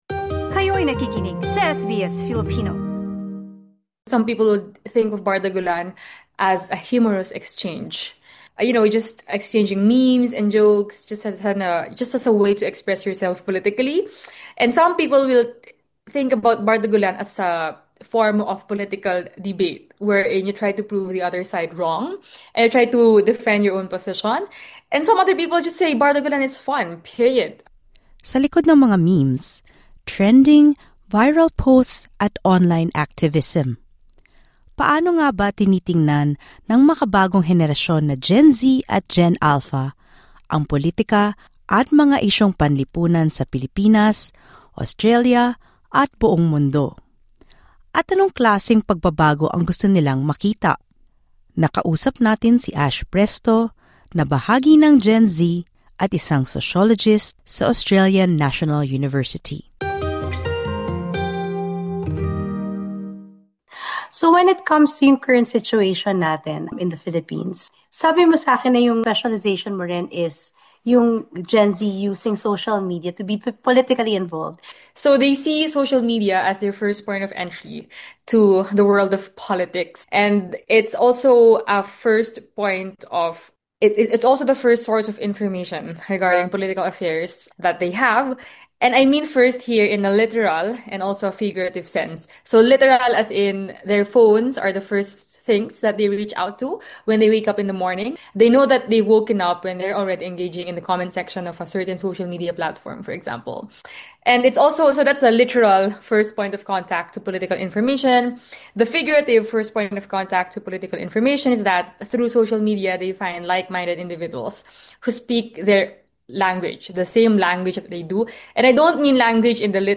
While resilience has been seen as an outstanding trait of Filipinos, it can become a 'crutch'. Listen to the interview